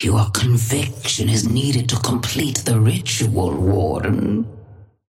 Sapphire Flame voice line - Your conviction is needed to complete the ritual, Warden.
Patron_female_ally_warden_start_01.mp3